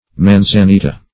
manzanita \man`za*ni"ta\ (m[a^]n`z[.a]*n[=e]"t[.a]), n. [Sp.,